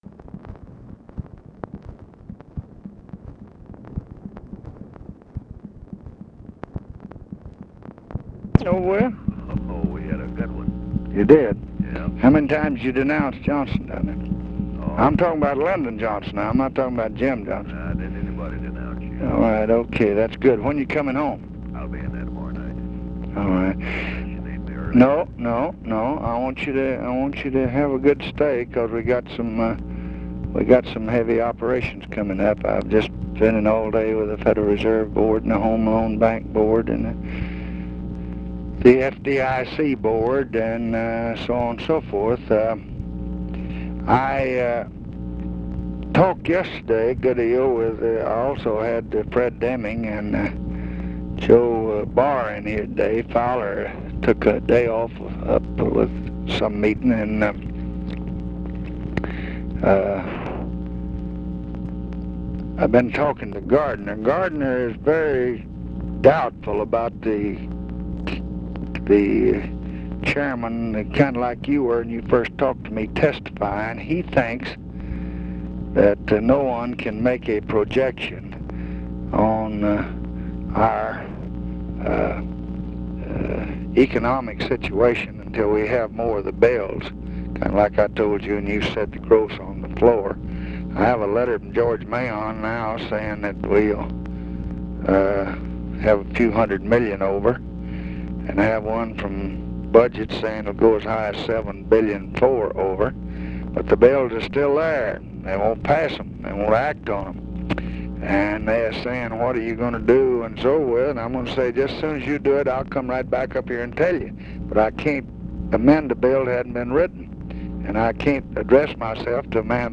Conversation with WILBUR MILLS, September 17, 1966
Secret White House Tapes